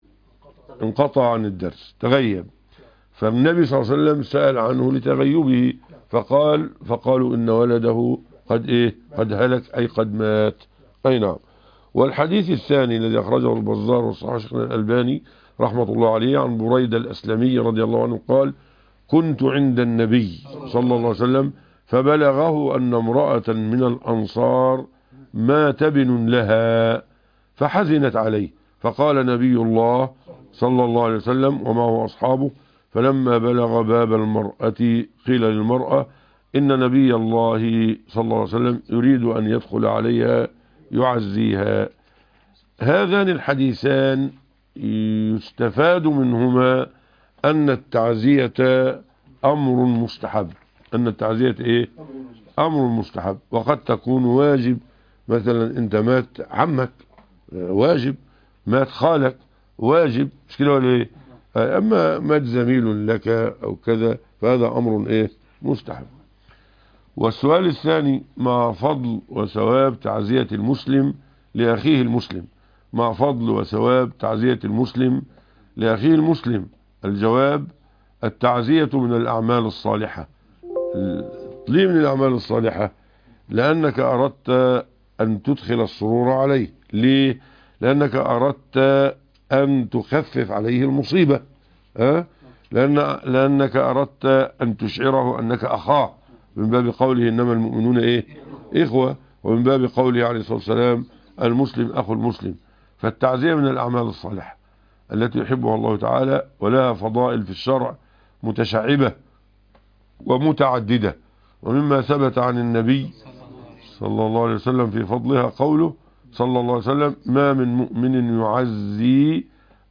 بث مباشر للقاء الفتاوى